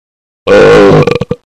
Riaugėjimo garsai
Garsus, krentanti intonacija